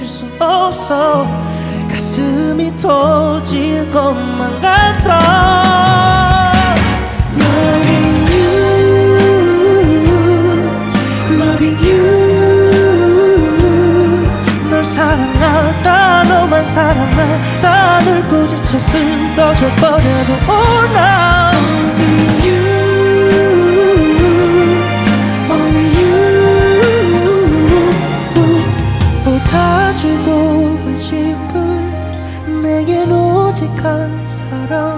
K-Pop Pop
2015-11-10 Жанр: Поп музыка Длительность